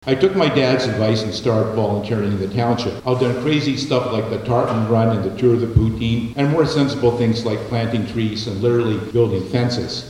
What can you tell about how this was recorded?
The nominees gathered at McNab School September 22nd for an All-Candidate Forum, hosted by the Greater Arnprior Chamber of Commerce.